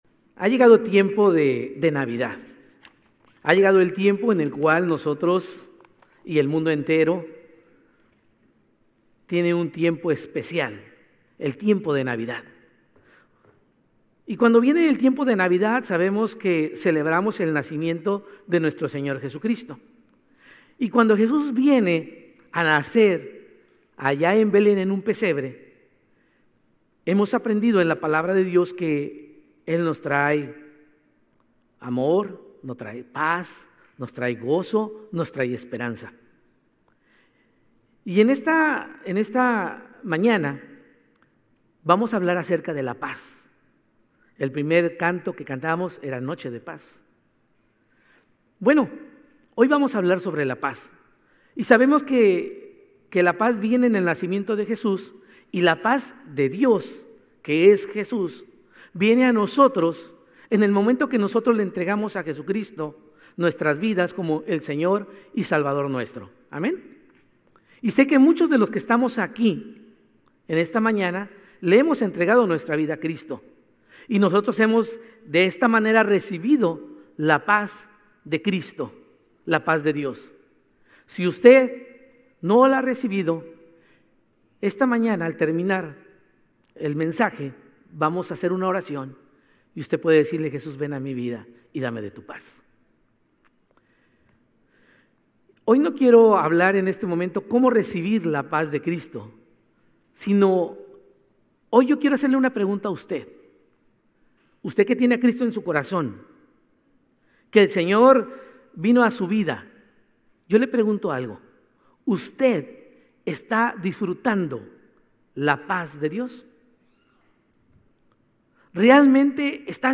Sermones Grace Español 12_7 Grace Espanol Campus Dec 08 2025 | 00:41:54 1x 00:00 / 00:41:54 Subscribe Share RSS Feed Share Link Embed